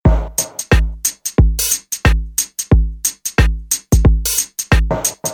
reggae drumloops soundbank 2